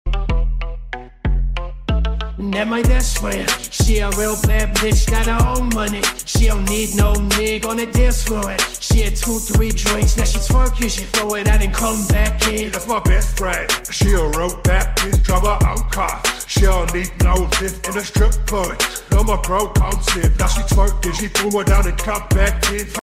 AI cover